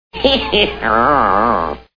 Looney Toons TV Show Sound Bites